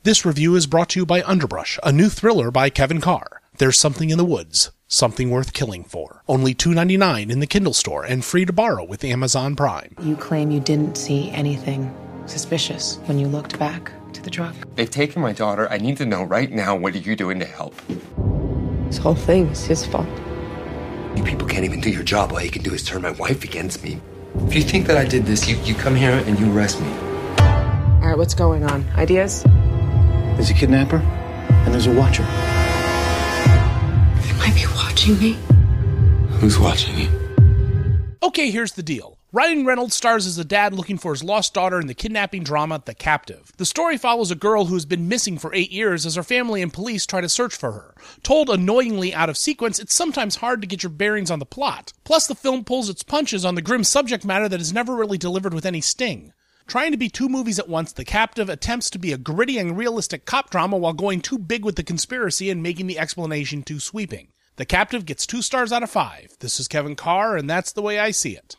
The Captive’ Movie Review